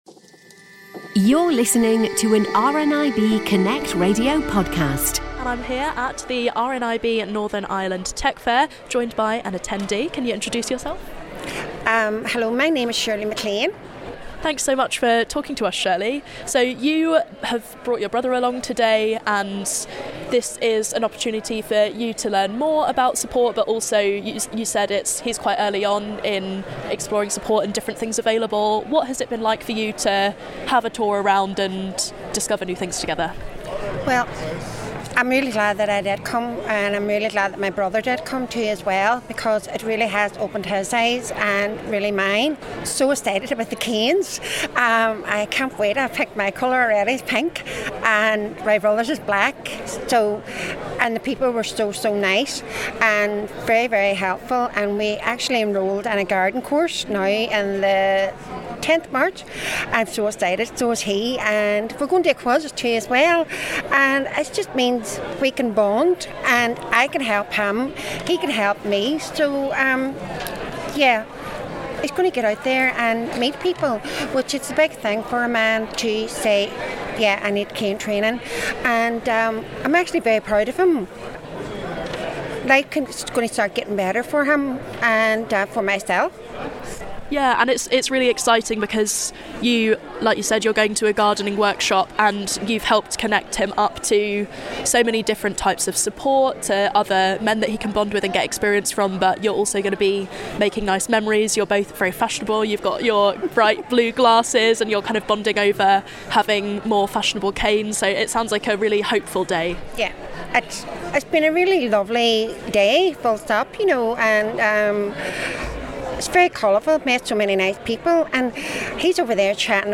RNIB Northern Ireland Technology Fair 2026 took place on Wednesday 11th of February at the Foyle Arena in Derry/Londonderry.